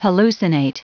Prononciation du mot hallucinate en anglais (fichier audio)
Prononciation du mot : hallucinate